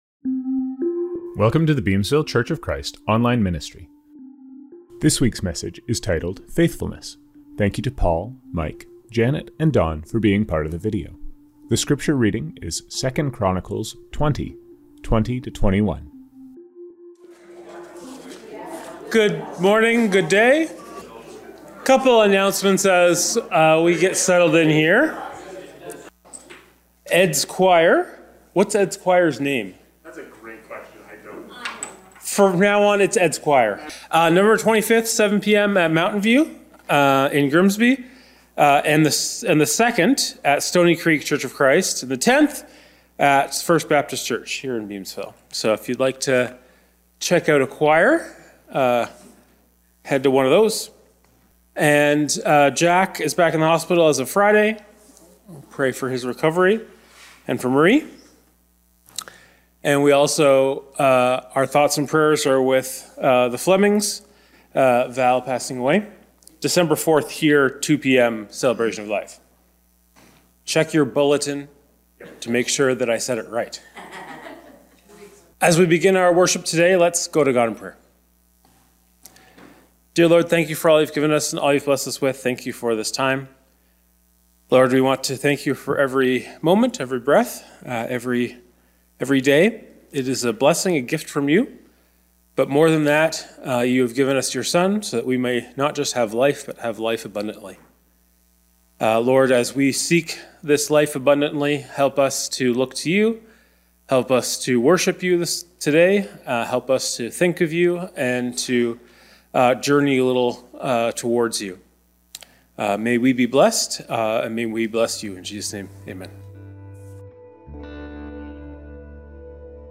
Scriptures from this service: Communion – Hebrews 11:4; John 3:16.